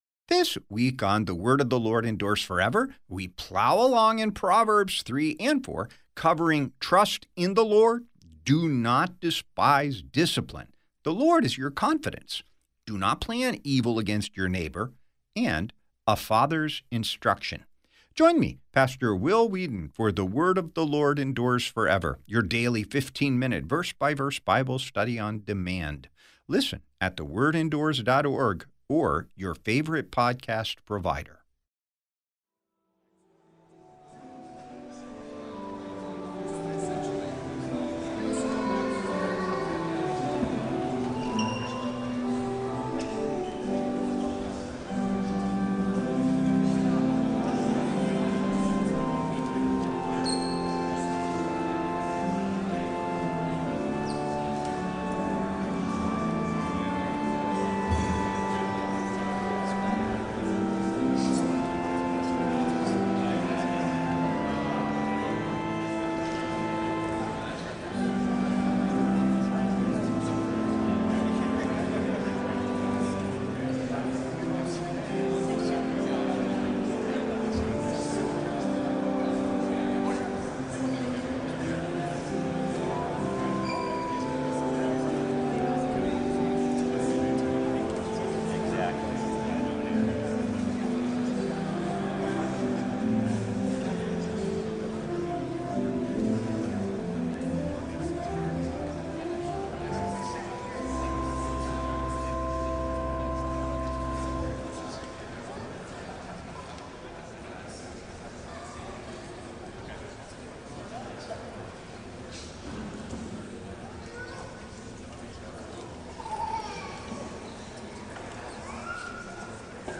On the campus of Concordia Theological Seminary, Fort Wayne, Indiana.